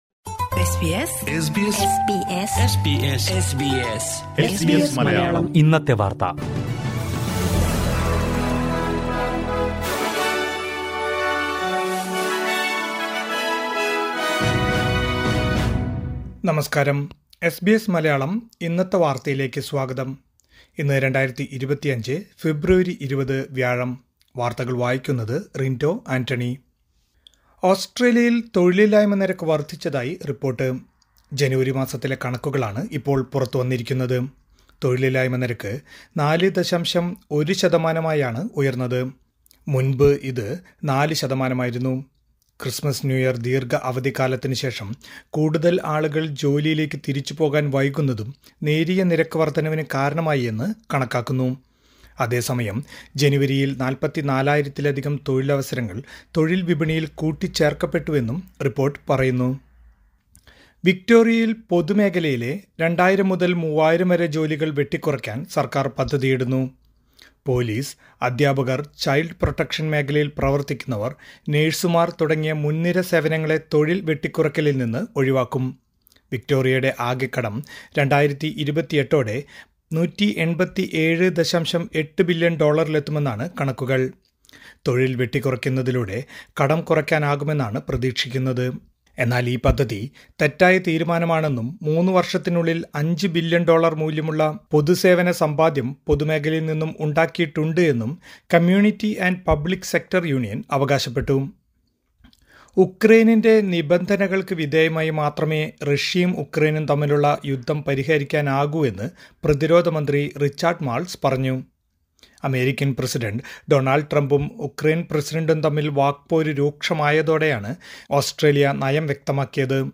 2025 ഫെബ്രുവരി 20ലെ ഓസ്‌ട്രേലിയയിലെ ഏറ്റവും പ്രധാന വാര്‍ത്തകള്‍ കേള്‍ക്കാം...